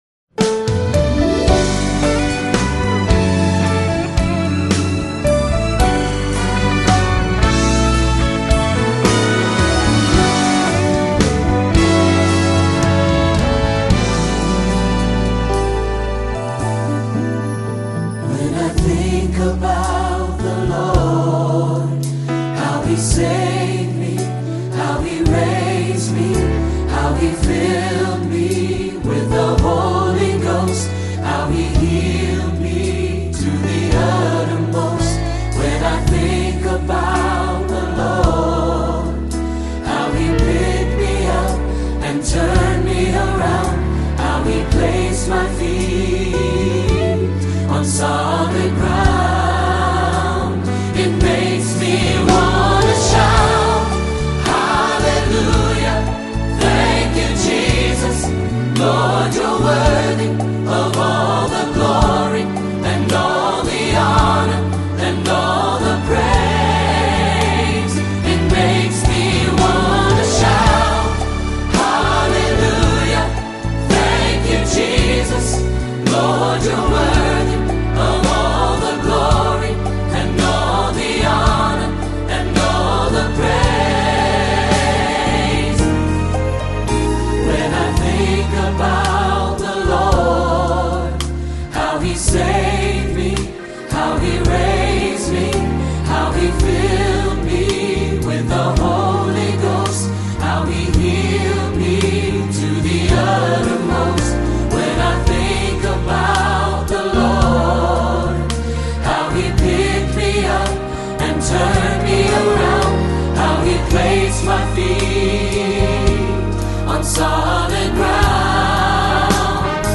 Summer Choir Anthems